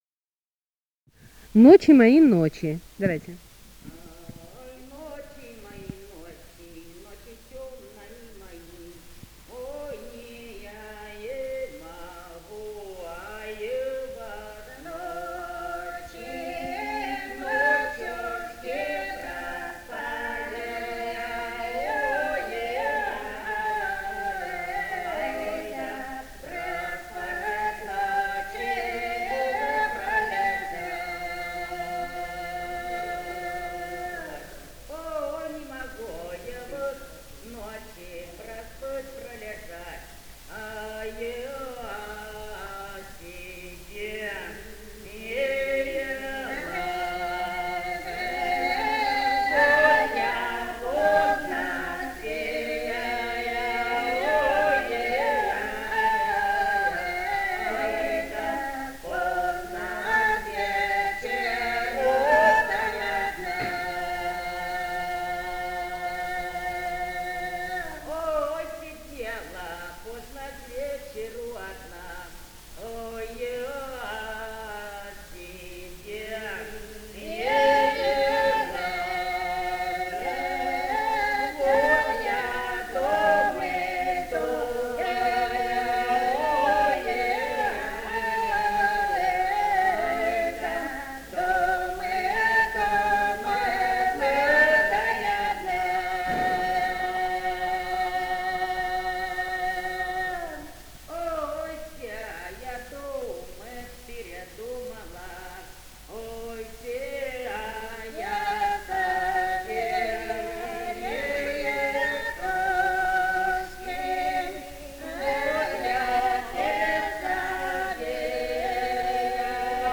Свиридова И. К. Этномузыкологические исследования и полевые материалы 197. «Ай, ночи, мои ночи» (лирическая). Пела женская группа хора
Ростовская область, ст. Вёшенская, 1966 г. И0939-04